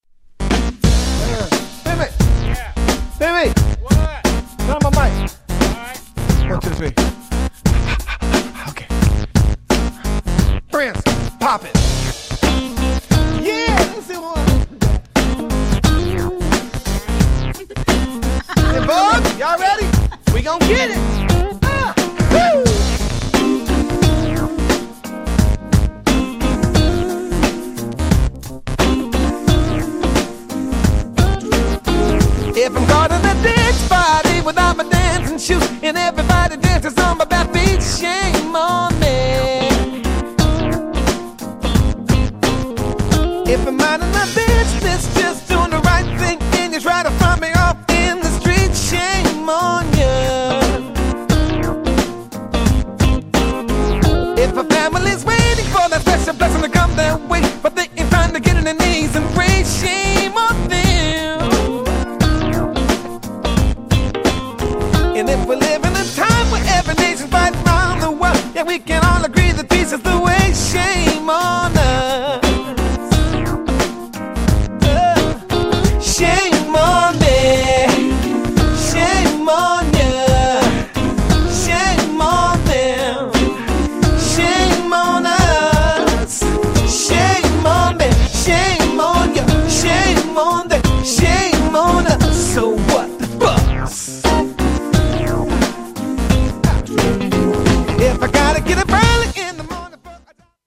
GENRE R&B
BPM 101〜105BPM